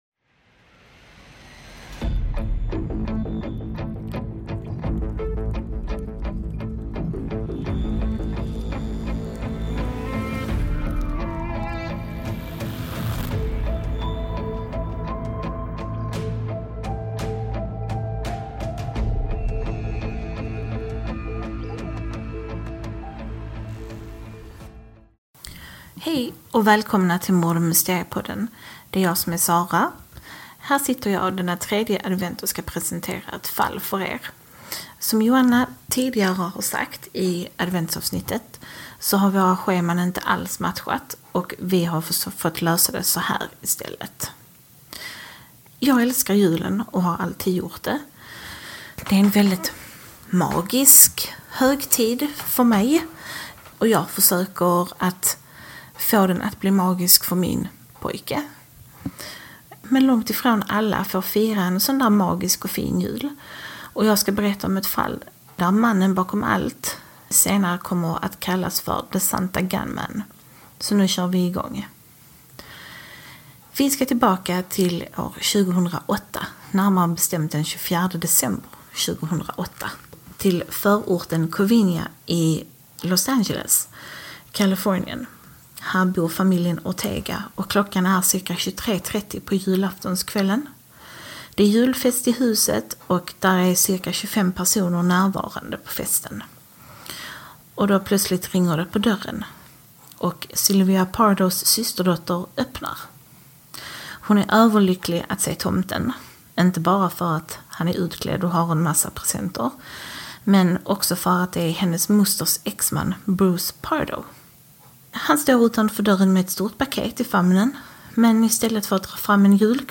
En vanlig diskussionspodd om ovanliga mordfall och mysterier. Följ med två tjejkompisar när de gör en djupdykning i de obehagligaste fallen de känner till.